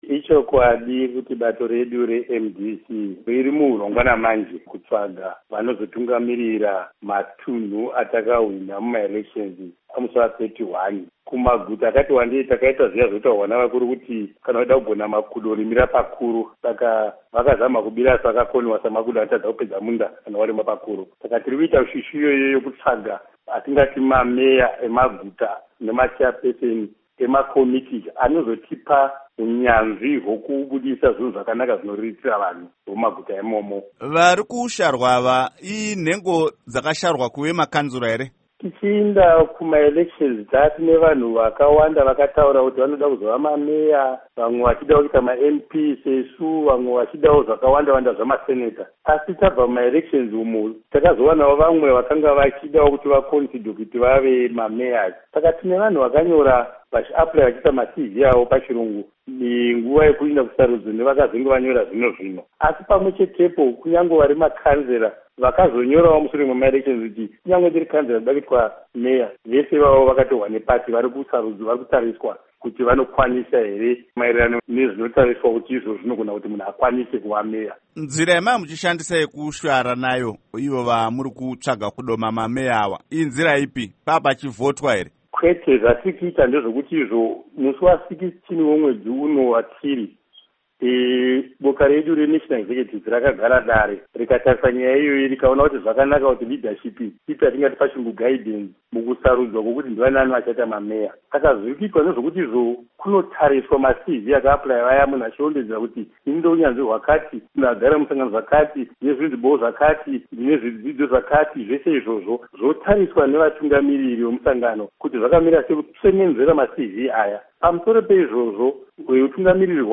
Hurukuro naVaBlessing Chebundo